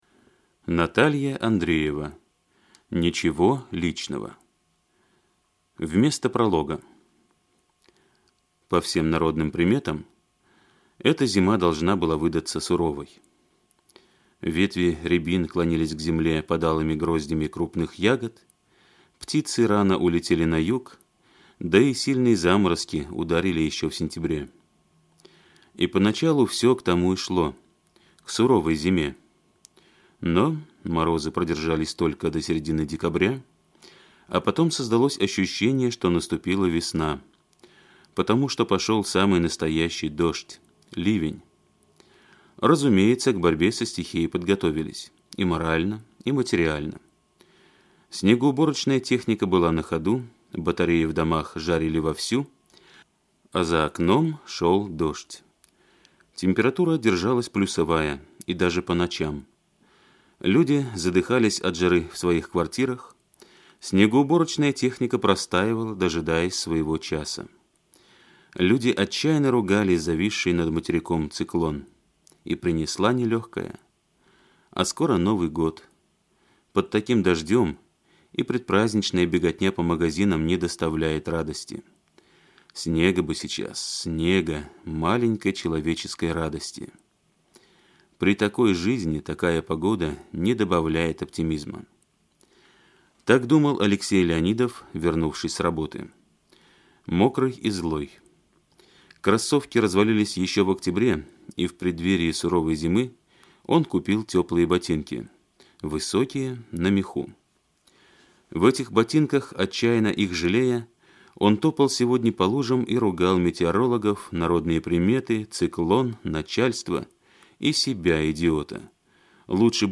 Aудиокнига Ничего личного